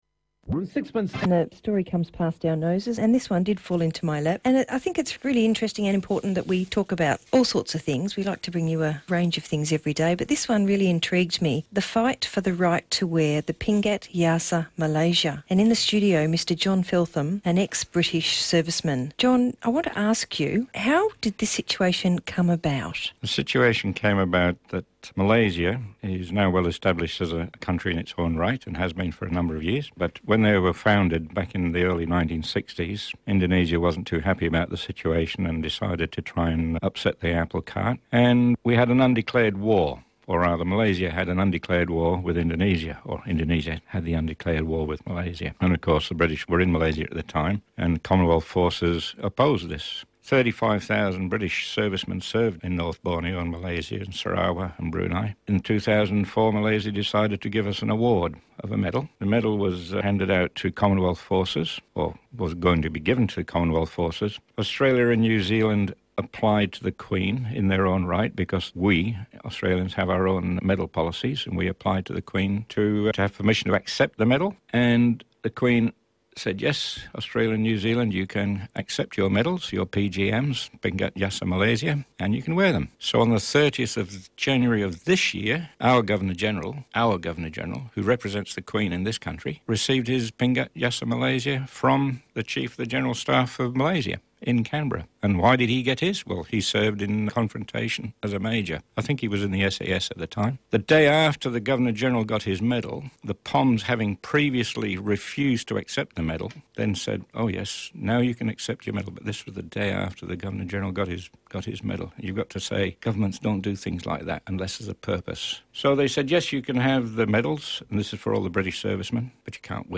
Today the radio interview went to air....
The interviewer is so spell-bound that she just sits there and listens.
This is a must listen to broadcast from a friend and fellow Malaysian War Veteran now living in Australia, an anomaly so bizarre you would think it was an April Fools Day joke